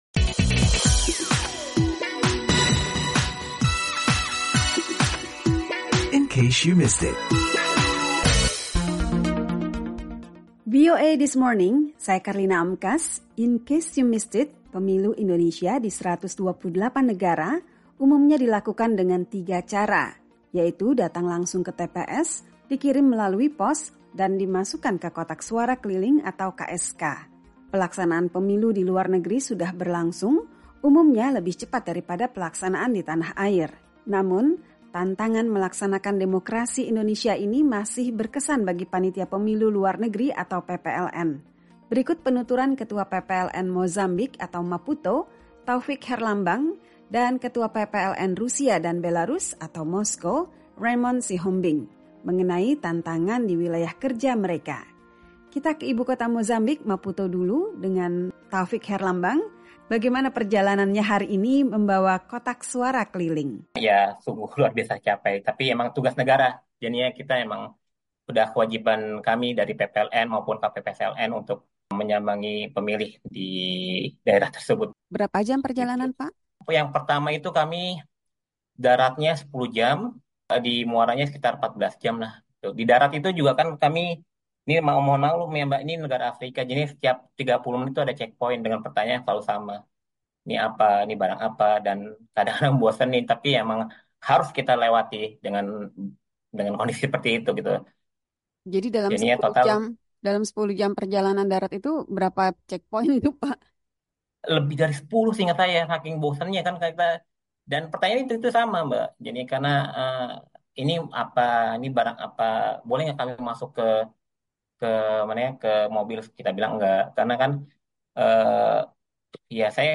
berbincang dengan panitia pemilihan luar negeri (PPLN) di Mozambik serta Rusia dan Belarus, yang menceritakan tantangan mereka dalam menjemput suara pemilih dengan membawa kotak suara keliling (KSK) dalam pemilu kali ini.